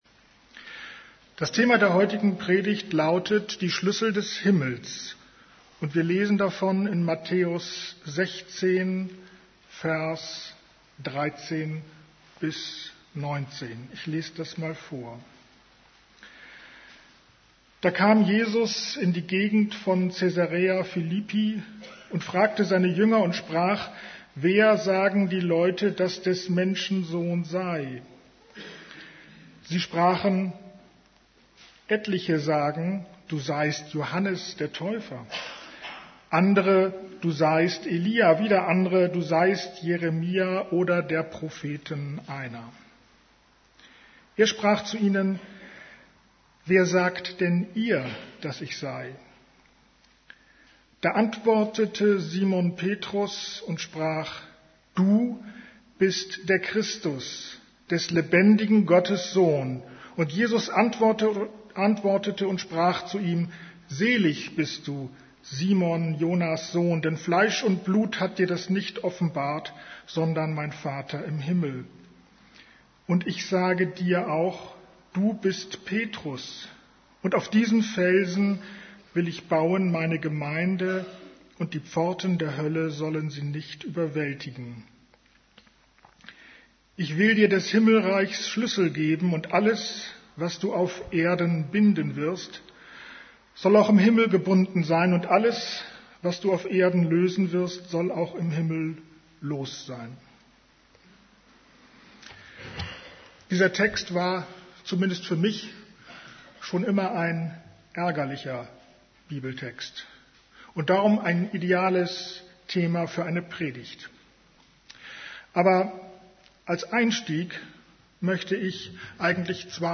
> Übersicht Predigten Die Schlüssel des Himmels Predigt vom 15.